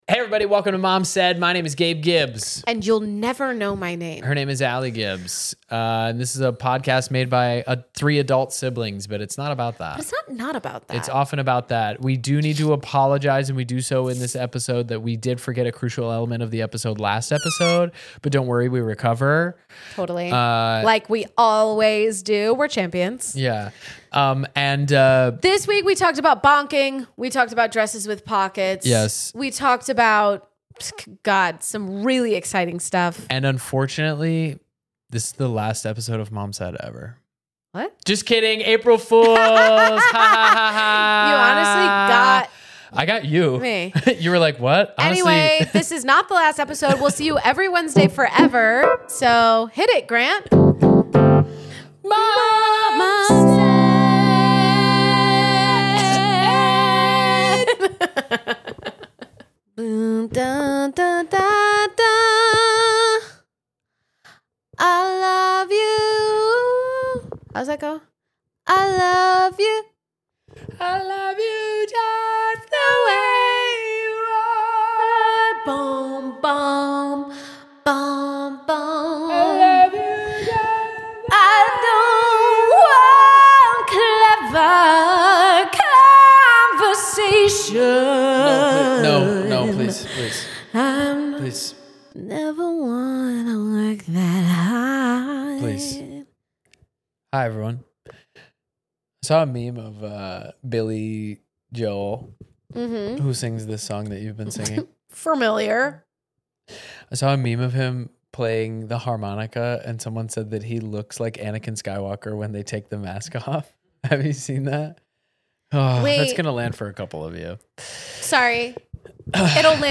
Does head skin feel weird? Do we really need dresses with pockets? Please forgive us as we venture into this week’s episode of three adult siblings arguing (mom said!).